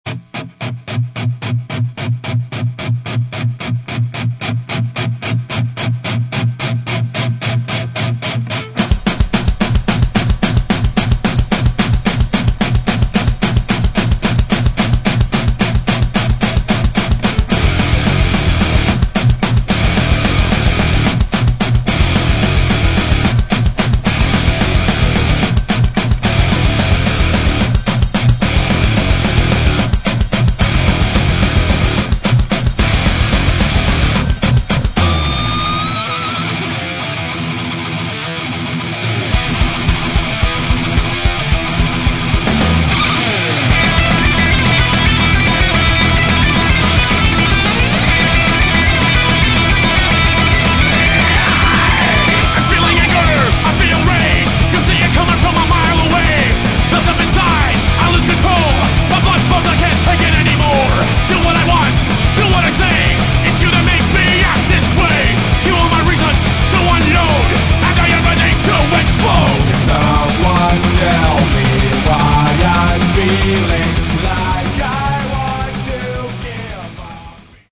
Metal
Эффекты, обработки, качественная в общем запись.